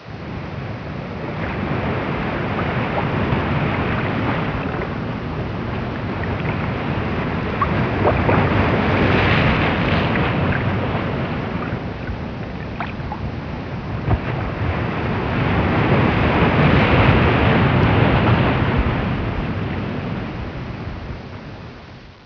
Free Sound Effects
Ocean.mp3